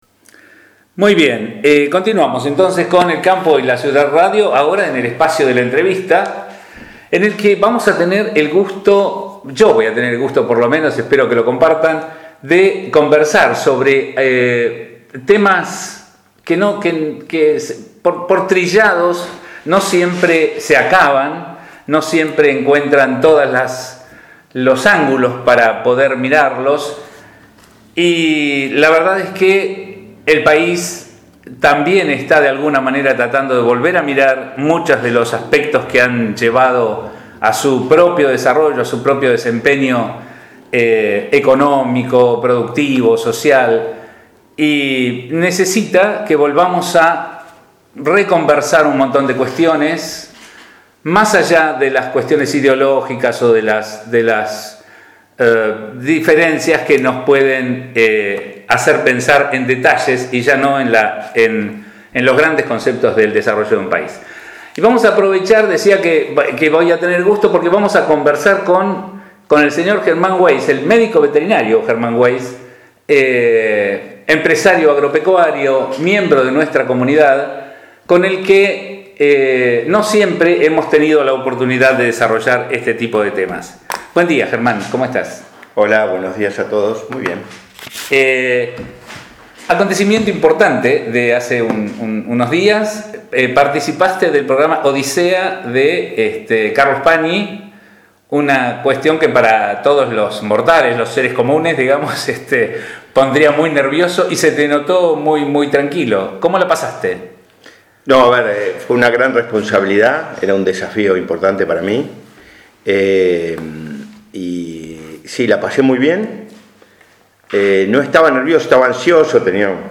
en El Campo y la Ciudad Radio por FM 104.1 en la ciudad de América, provincia de Buenos Aires